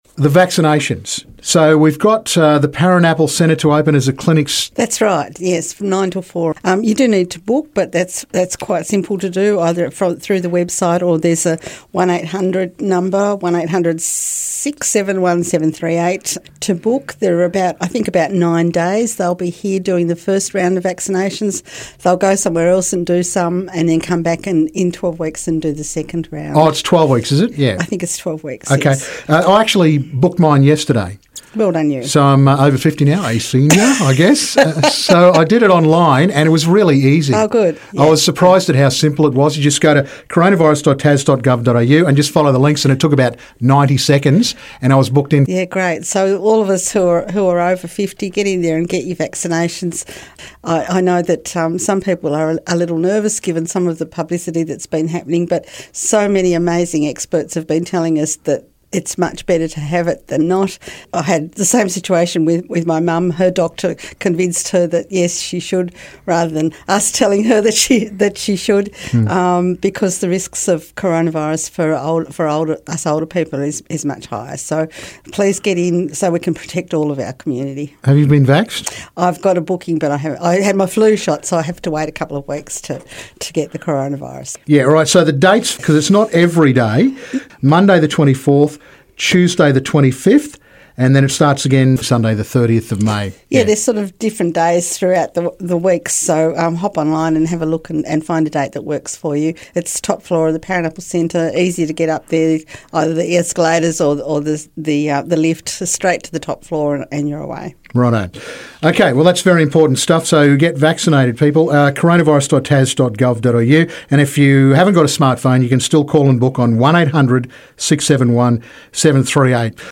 The Devonport Vaccination Clinic is open at the Paranaple Centre. Here's Mayor Annette Rockliff.